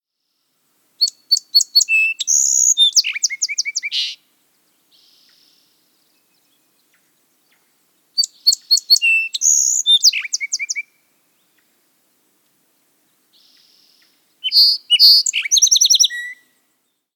Song Sparrow
How they sound: They sing a loud clanking that typically starts with abrupt, well-spaced notes and finishes with a buzz or trill.